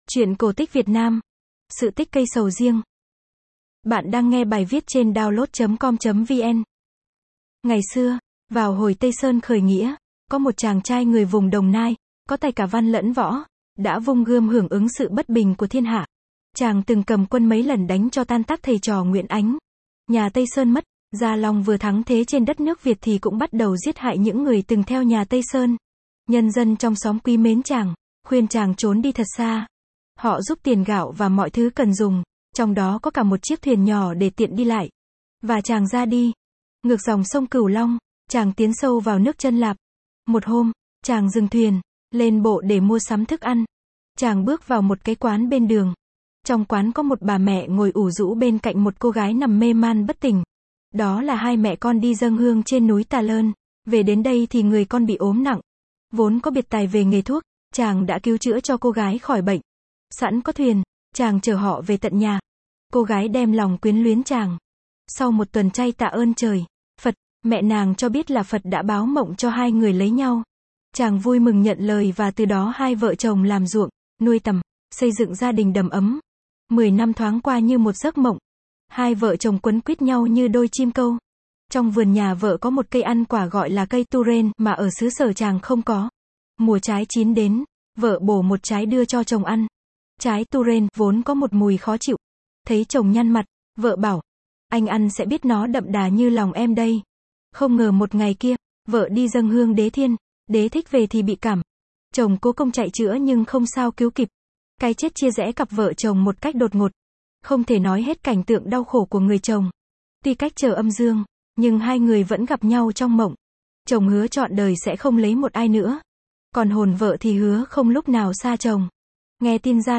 Sách nói | sự tích trái sầu riêng